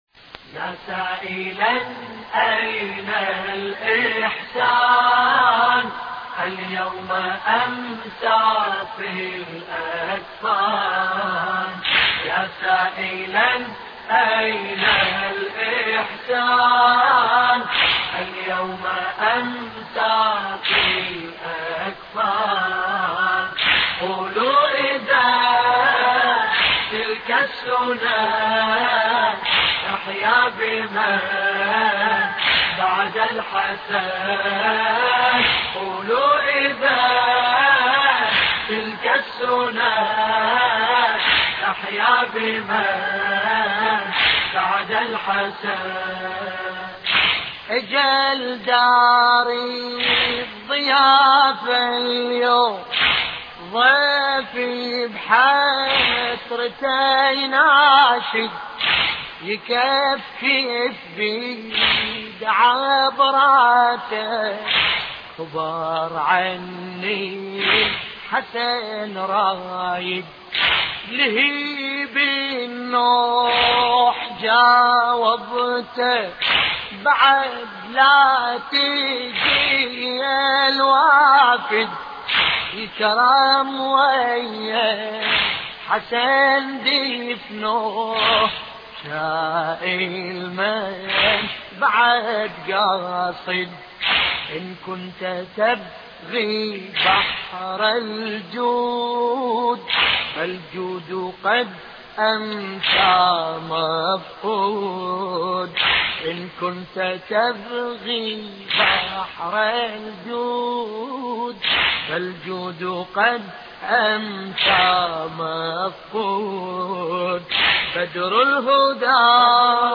مراثي الامام الحسن (ع)